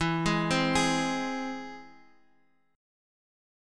E+ chord